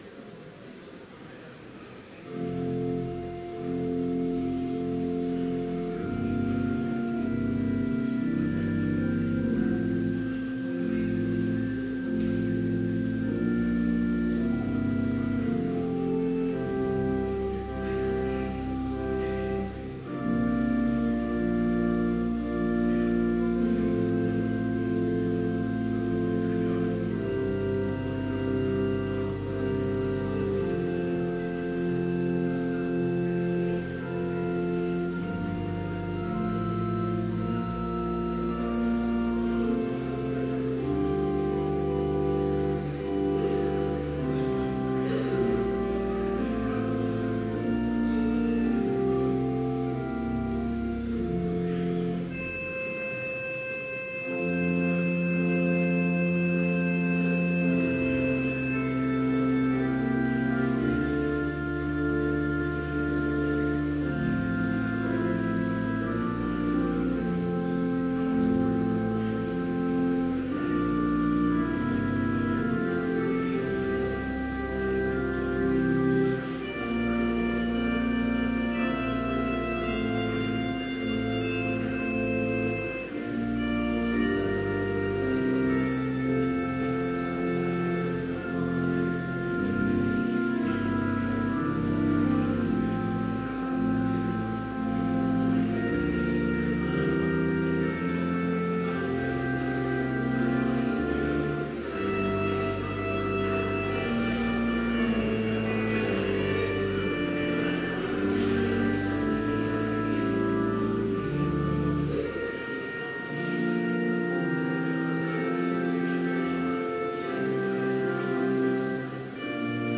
Complete service audio for Chapel - January 24, 2017